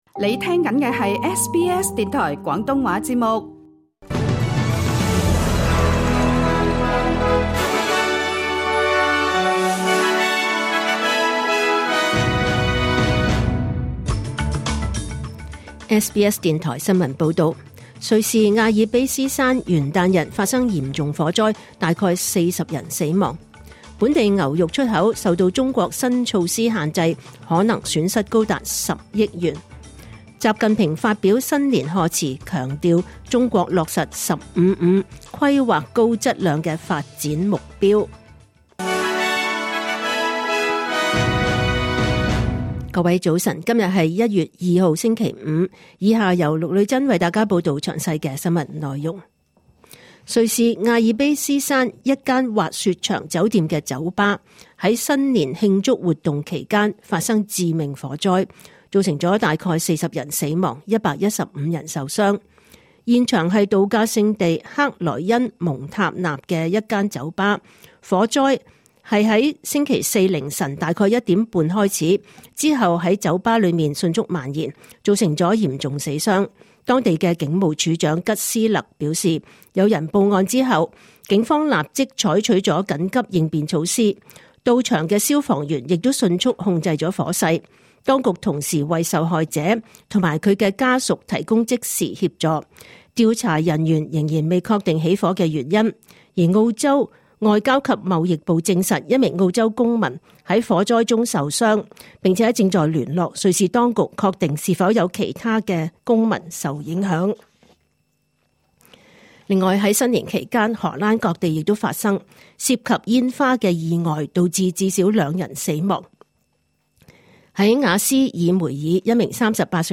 2026年1月2日SBS廣東話節目九點半新聞報道。